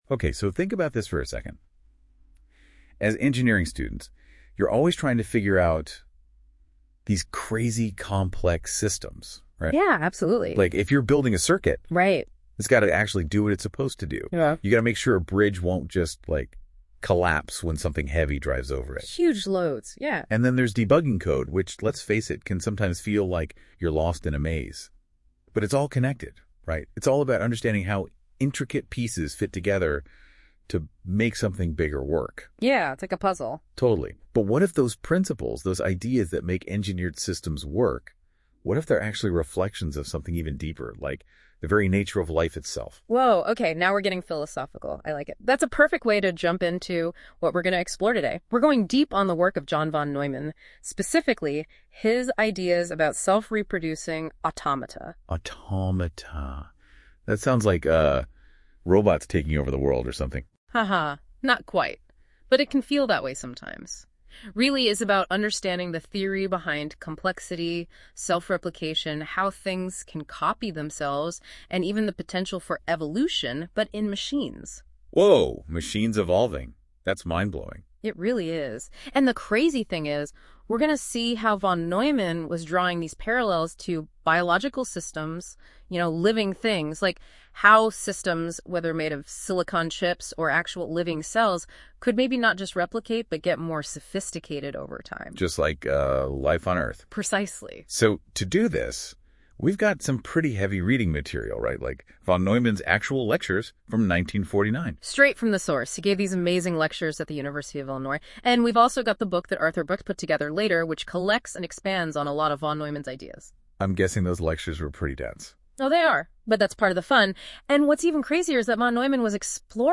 A companion AI-Generated podcast via Google NotebookLM is also available for fun (but it does not substitute the lecture notes at all! it brings up many connections not really in the argument, it is provided for fun only.)